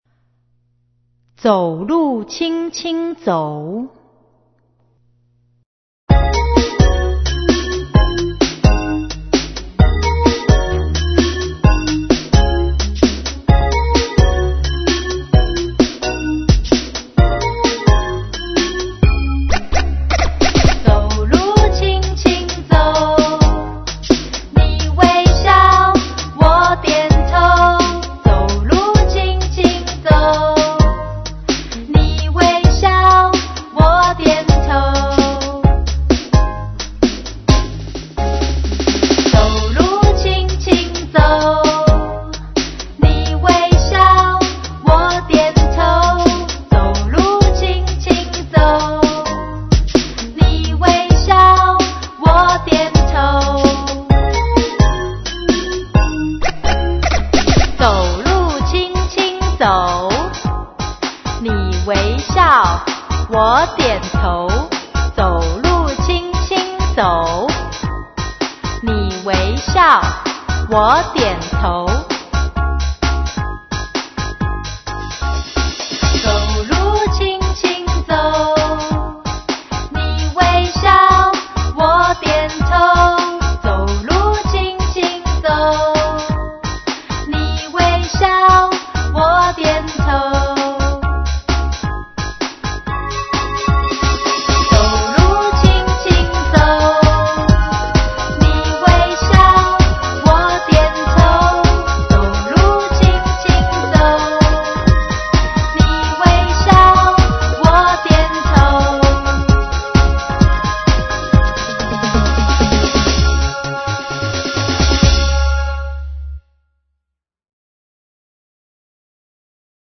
CD每首兒歌均譜寫輕快活潑的唱曲，增加幼兒學習情趣